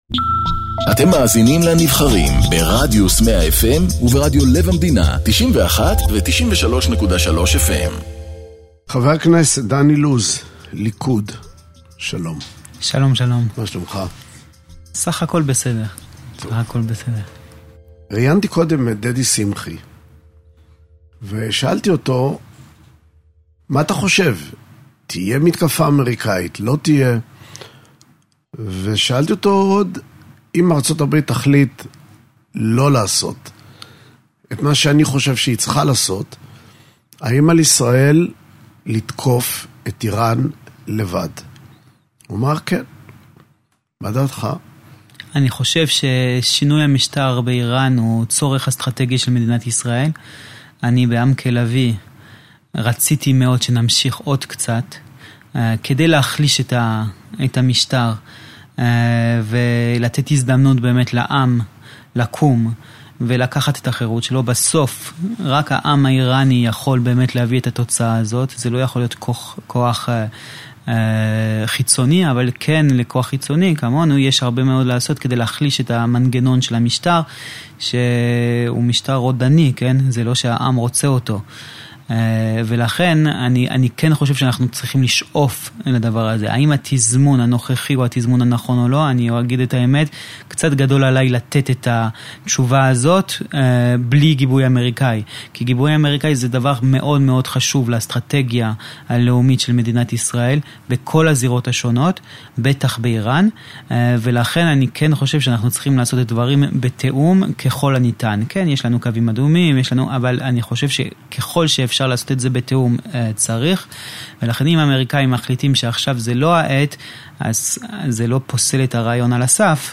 מראיין את חבר הכנסת דן אילוז